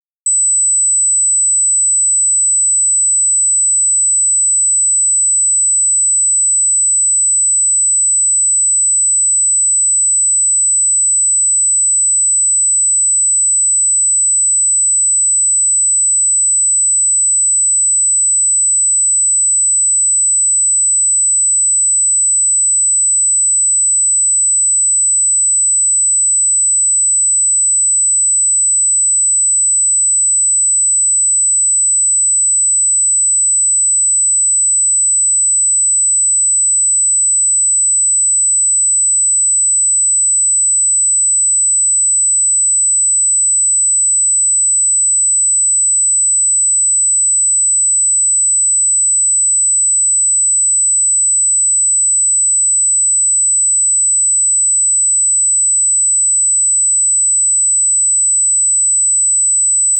Left vs Right Ear - 8 000 hz - Doctor Sound.mp3